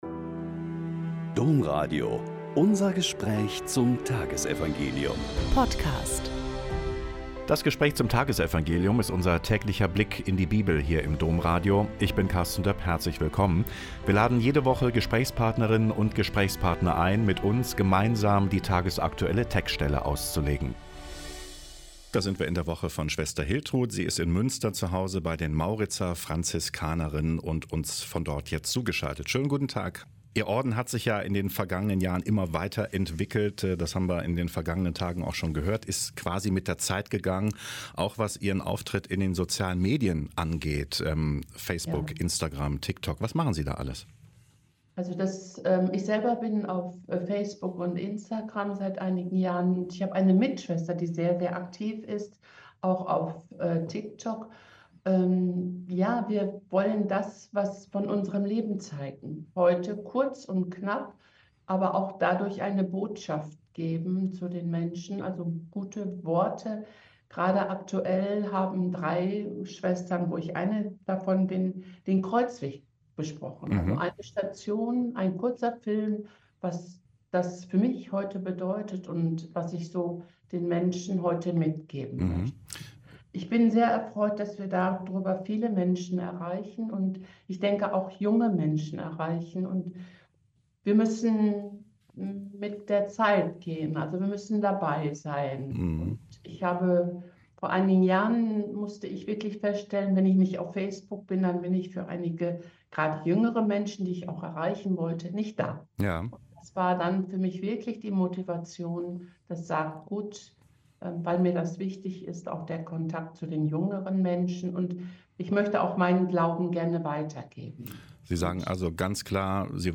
Joh 8,31-42 - Gespräch